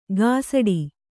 ♪ gāsaḍi